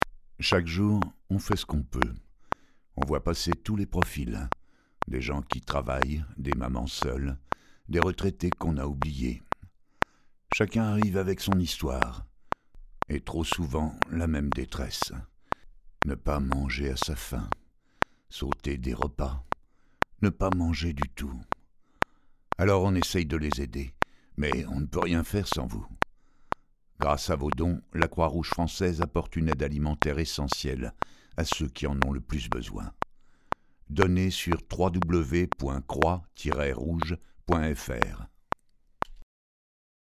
Voix off
Demo Croix Rouge
Jovial, chaleureux ou autoritaire au théâtre ou au micro, "à mon insu de mon plein gré", ma voix grave est déterminante, aussi ai-je appris à en sortir))).